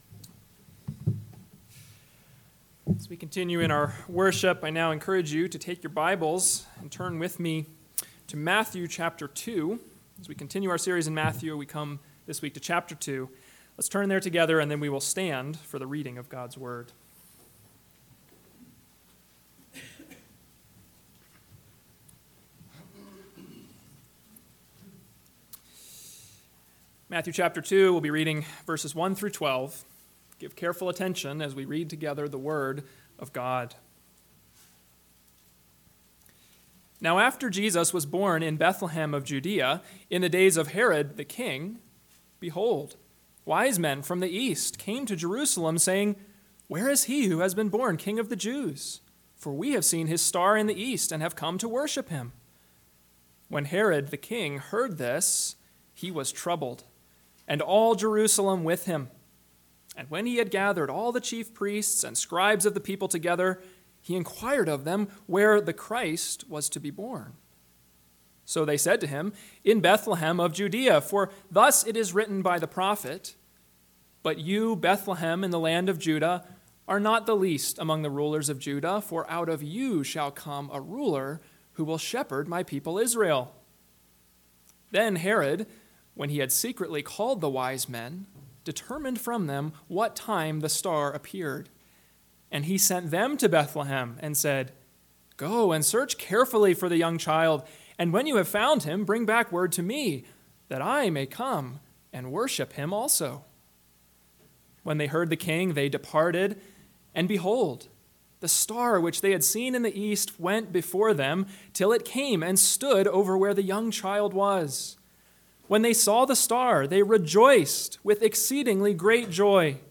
AM Sermon – 10/2/2022 – Matthew 2:1-12 – Where Is the King?